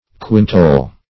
Search Result for " quintole" : The Collaborative International Dictionary of English v.0.48: Quintole \Quin"tole\, n. [It. quinto fifth.]